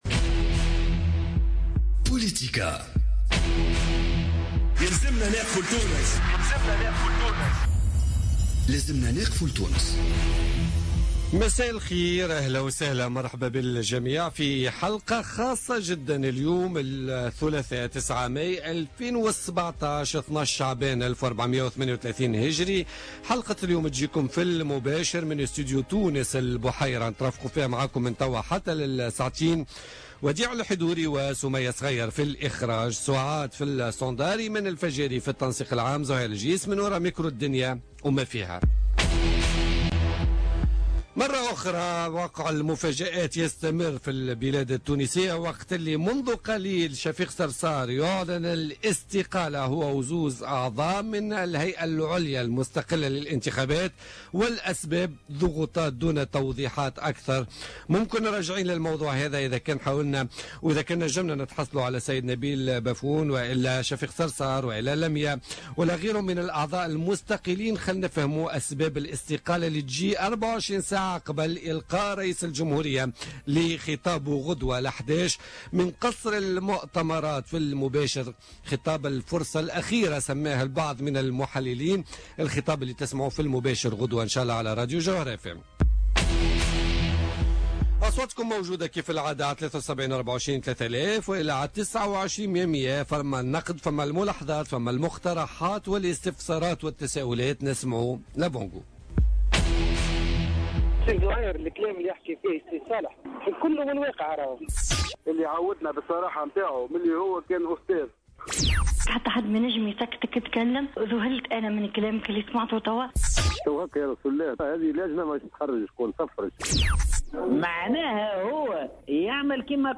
Slim Khalbous ministre de l'Enseignement supérieur et de la recherche scientifique, invité de Politica